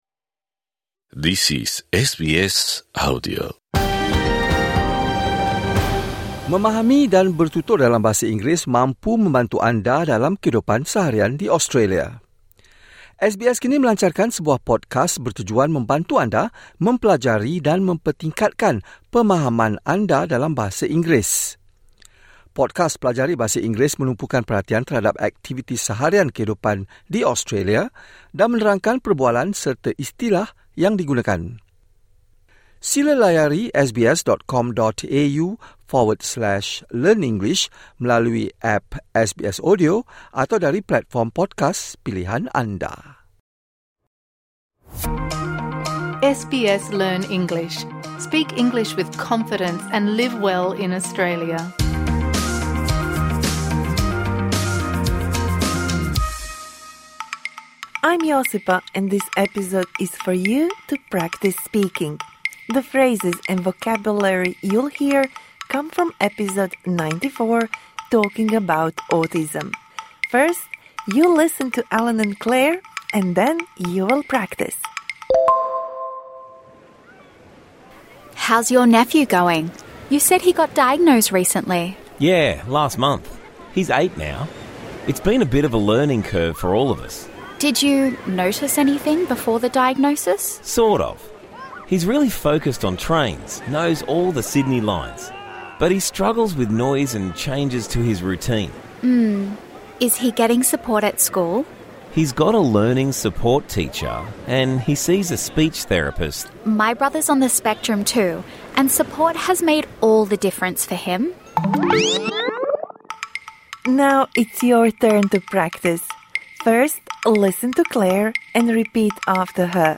This bonus episode provides interactive speaking practice for the words and phrases you learnt in #94 Talking about autism (Med).